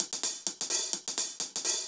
UHH_AcoustiHatA_128-02.wav